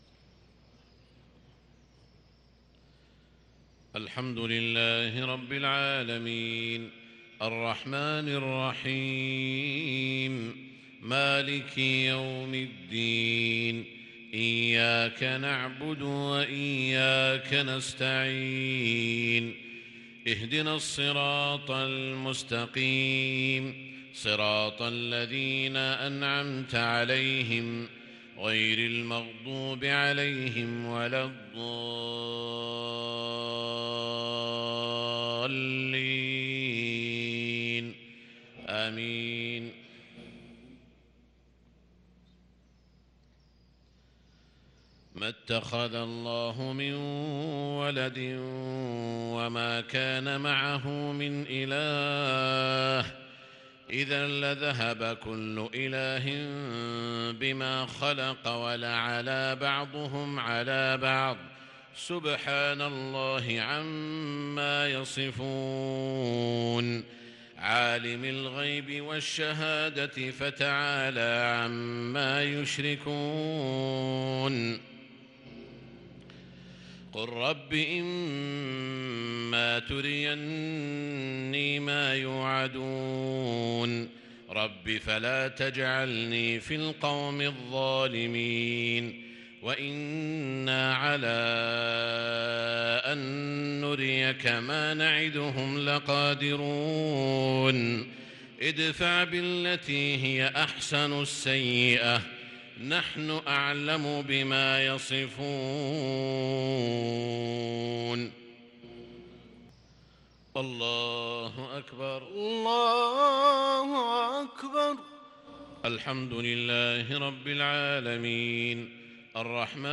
صلاة المغرب للقارئ سعود الشريم 12 جمادي الأول 1443 هـ
تِلَاوَات الْحَرَمَيْن .